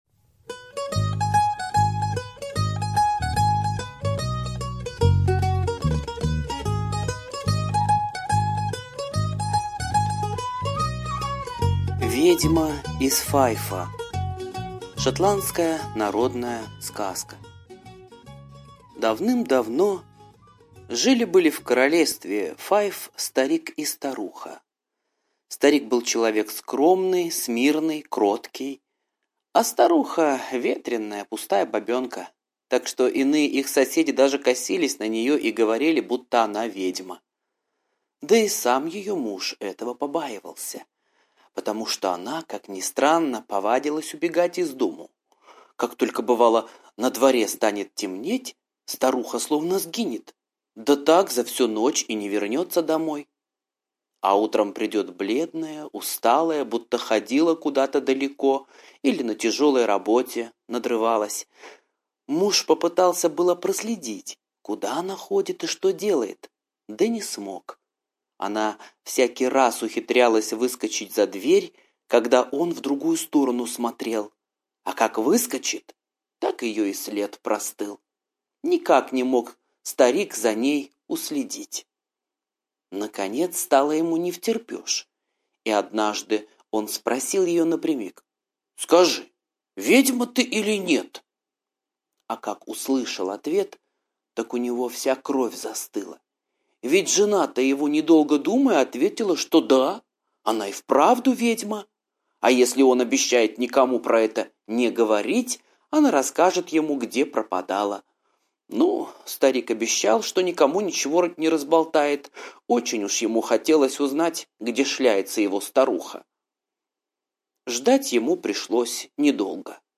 Ведьма из Файфа - скандинавская аудиосказка. Про старика, жена которого была ведьмой. Она со своими подругами на новолуние улетала из дома.